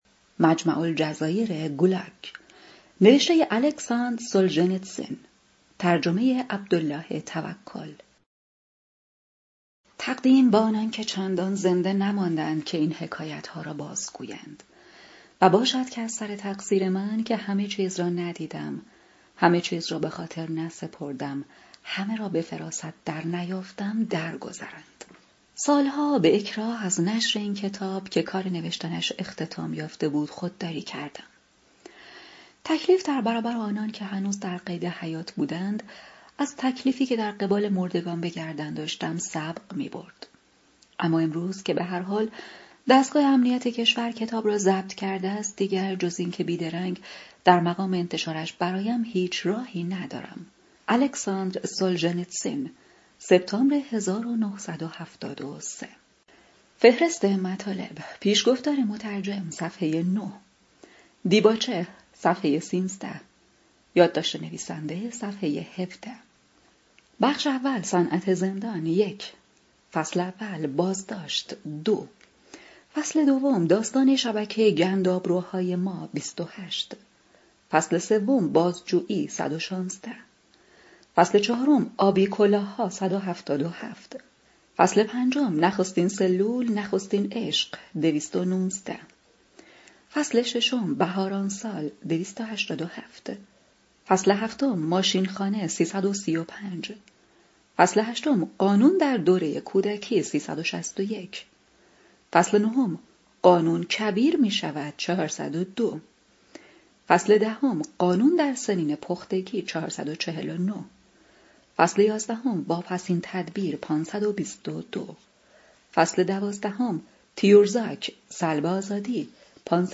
( کتاب صوتی )